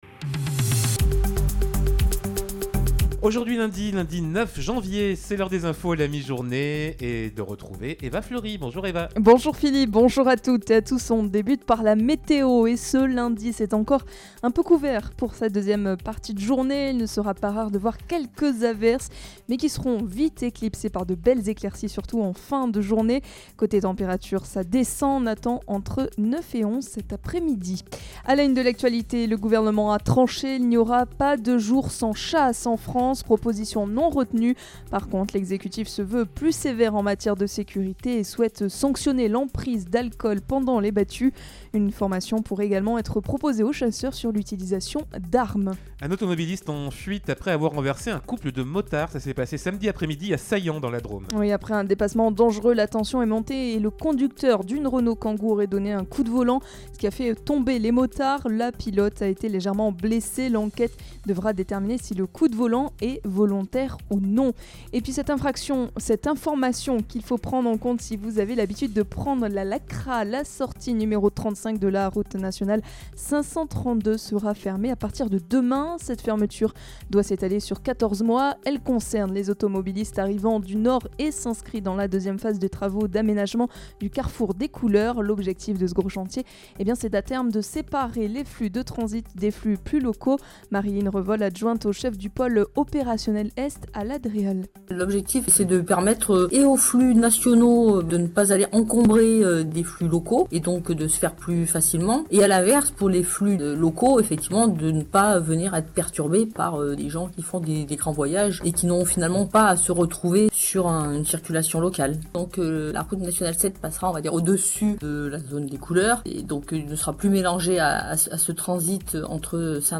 Lundi 09 janvier : le journal de 12h